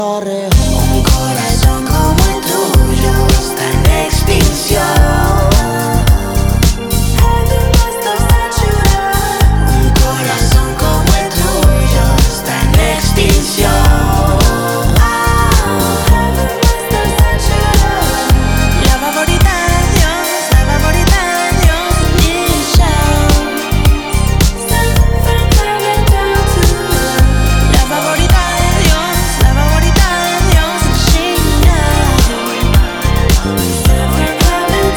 # Латино